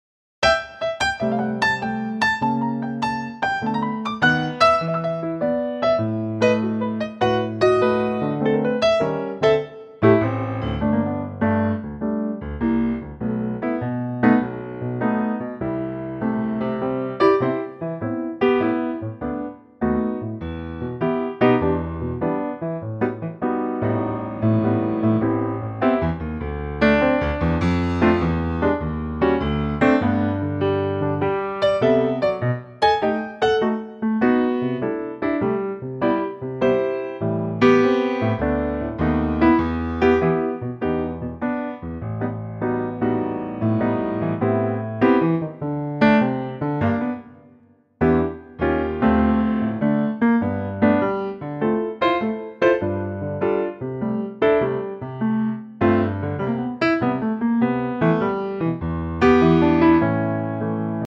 Unique Backing Tracks
key - G - vocal range - G to B